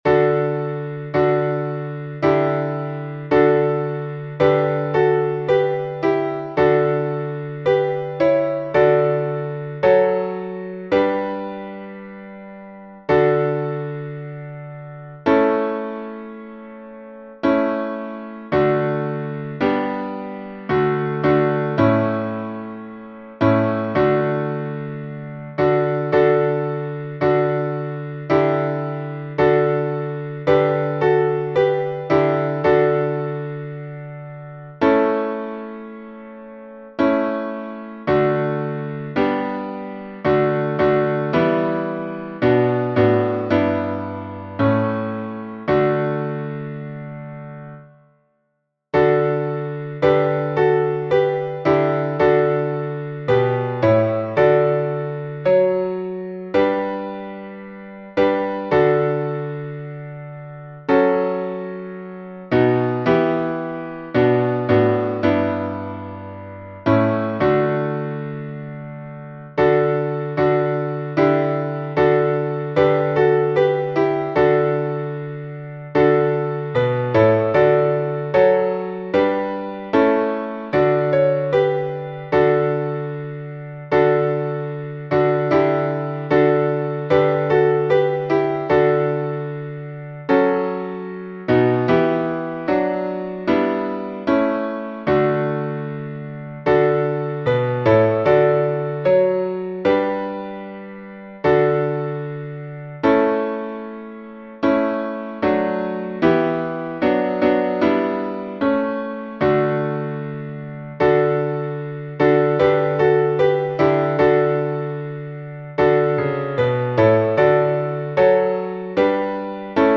Валаамский напев